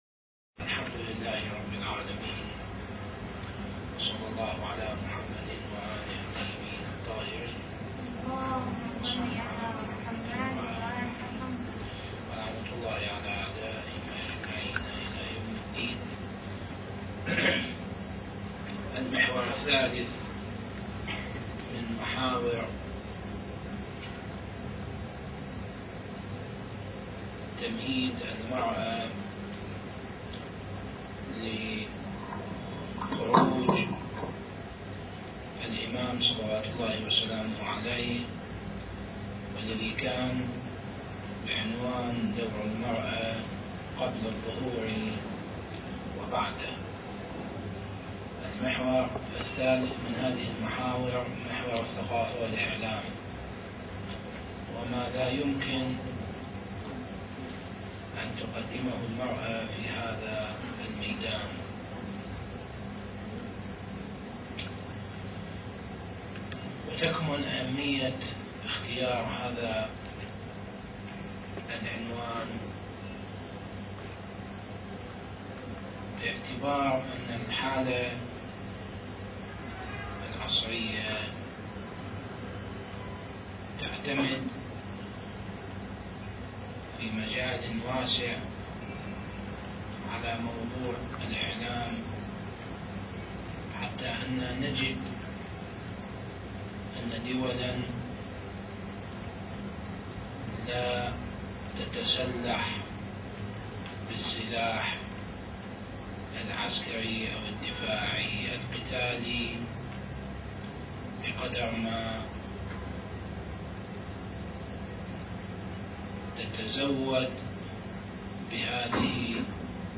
الدورة الثانية للاخوات المؤمنات التي أقامها مركز الدراسات التخصصية في الإمام المهدي (عجّل الله فرجه) والتي كانت تحت شعار (انتظار الدولة المهدوية الكريمة) التاريخ: 2005